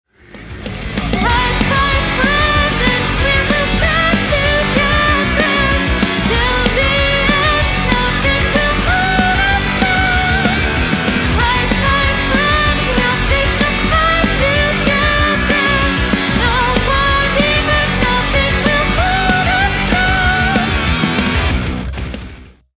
Hier ist mal ein kurzer Schnibbel mit Solaria light. Timing is irgendwie noch wackelig und aus einem Wort macht er irgendwie was anderes. Klingt auch ziemlich künstlich ...